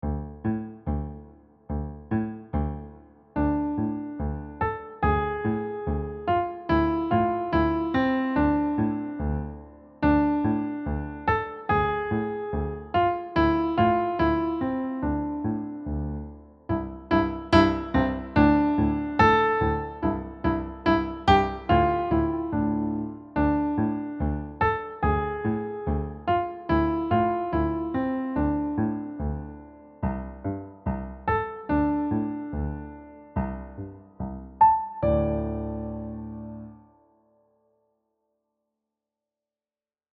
piano
Key: D minor pentascale with movement into C Major
Time Signature: 4/4 (march feel)